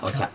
[okiak] adjective red